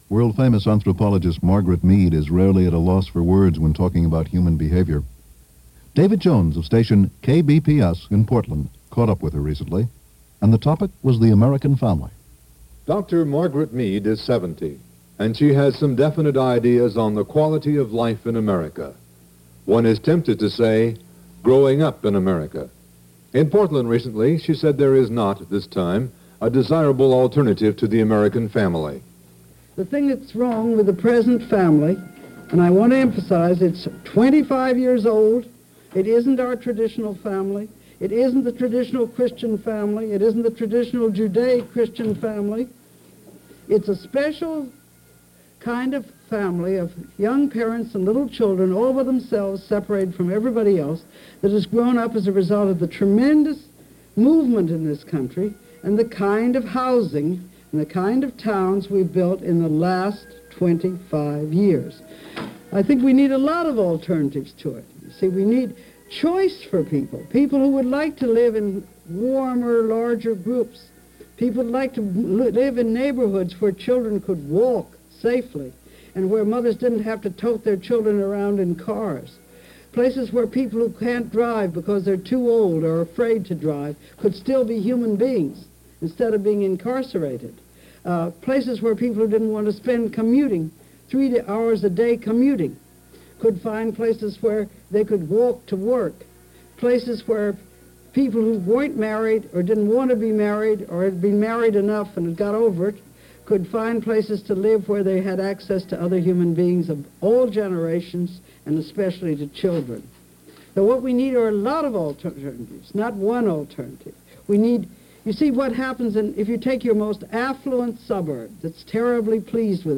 This brief interview – a little under four minutes, distills the problems which plagued 1970s America.